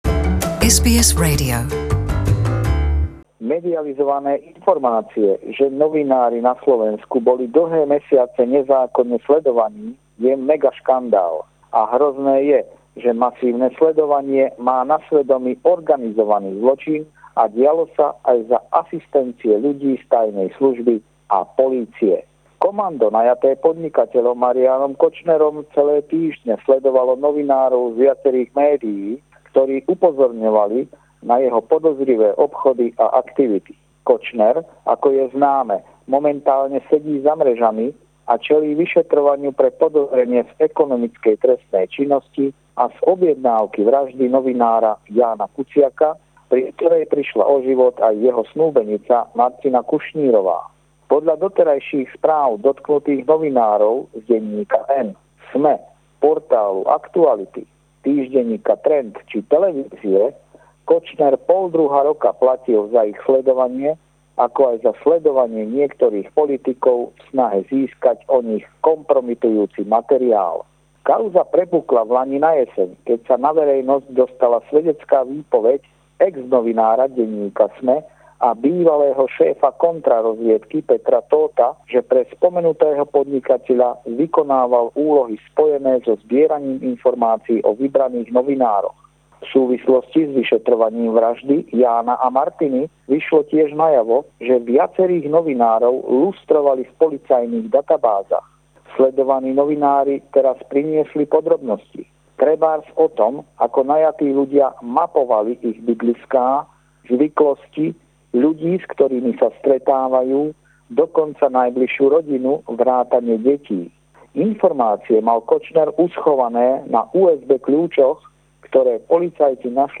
Pravidelný telefonát